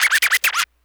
scratch.wav